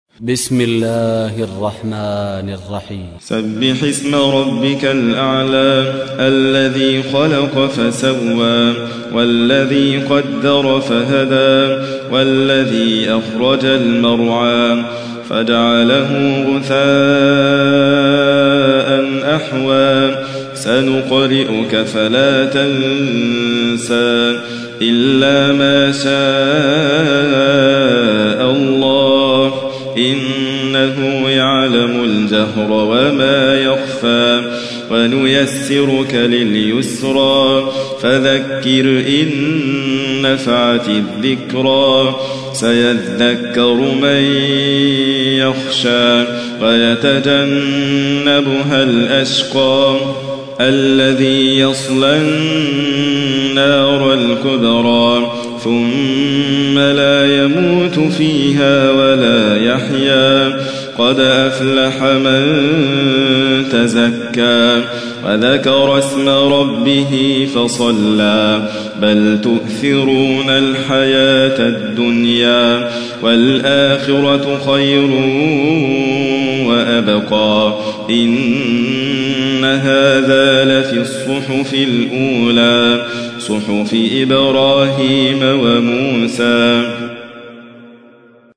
تحميل : 87. سورة الأعلى / القارئ حاتم فريد الواعر / القرآن الكريم / موقع يا حسين